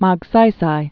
Mag·say·say
(mäg-sīsī), Ramón 1907-1957.